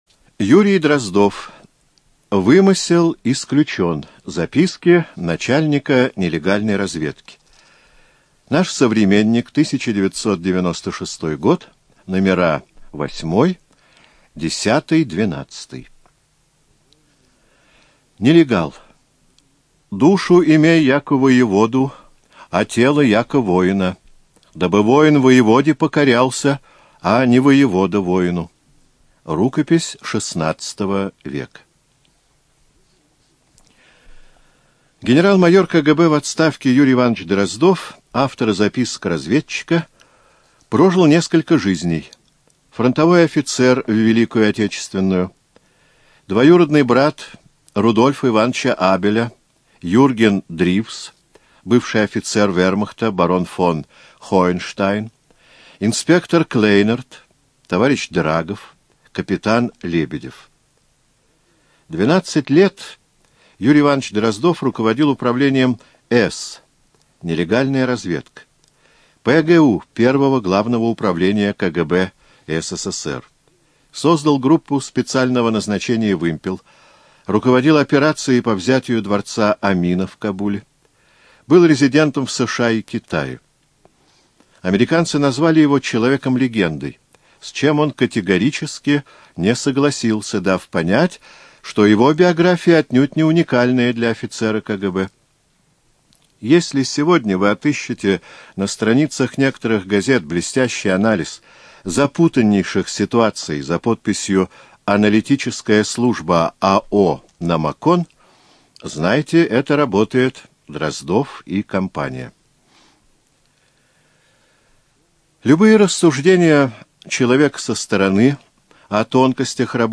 ЖанрБиографии и мемуары
Студия звукозаписиЛогосвос